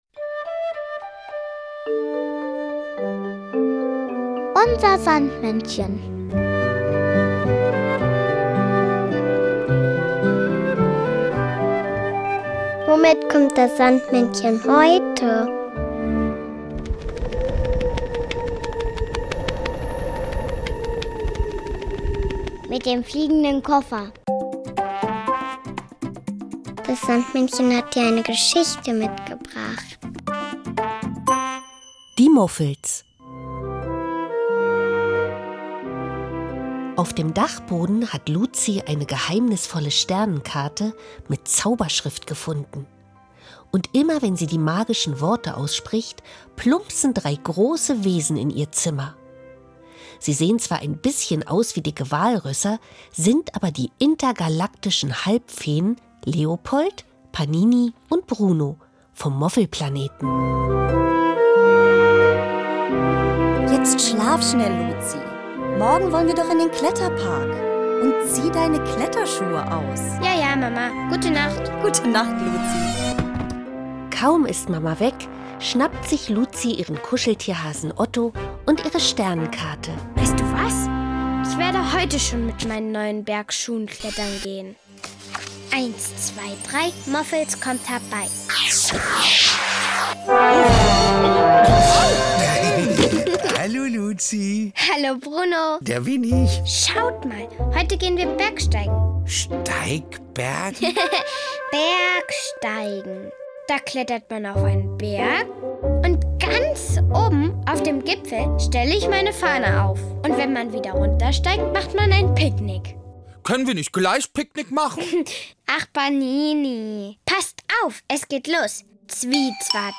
Geschichte mitgebracht, sondern auch noch das Kinderlied “Ich kann